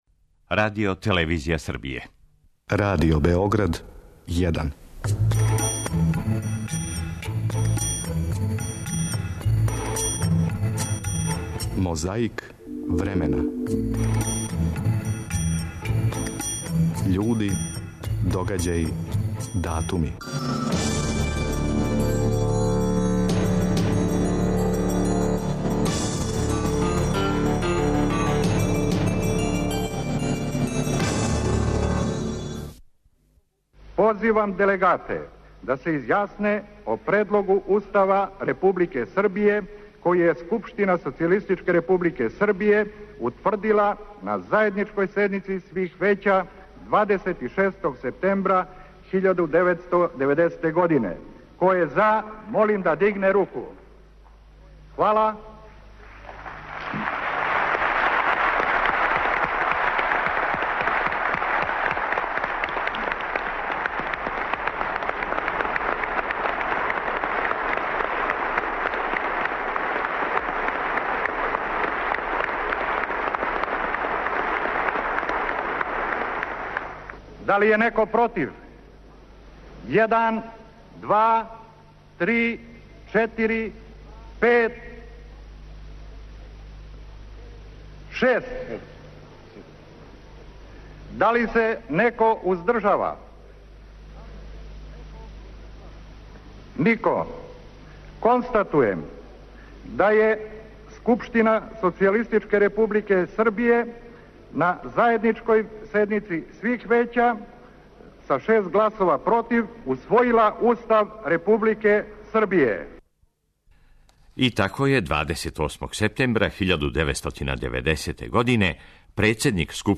Следи неколико коцкица из Мозаика, а прва каже - 28. септембра 1990. године, председник Скупштине републике Србије, Зоран Соколовић, уз пратеће аплаузе, прогласио је Устав СР Србије.
Деловима излагања неколико посланика подсећамо на време пре 20 година када нам је било како нам је било, а сада се сећамо да нам је било.
Председавајући је био председник скупштине Србије, Александар Бакочевић.